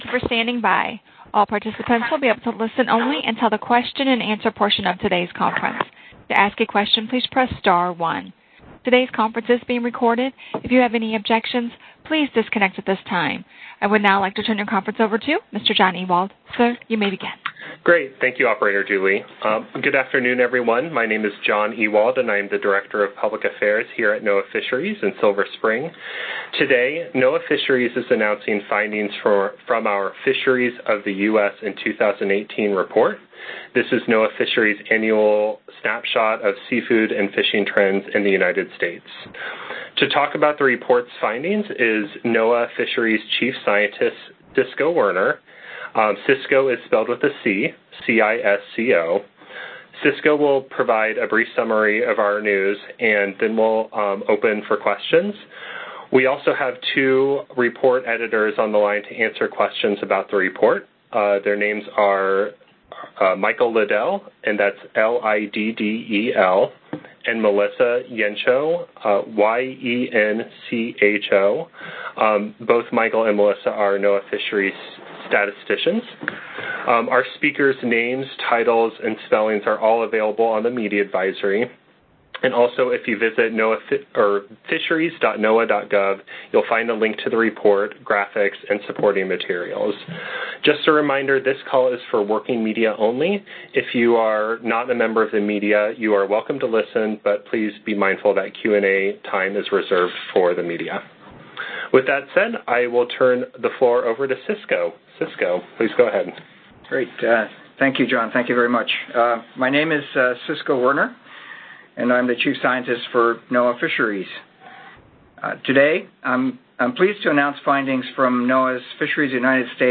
On Friday, February 21, NOAA Fisheries will hold a media teleconference to discuss findings from the Fisheries of the U.S. 2018 report (the latest fishing year for which extensive data is available).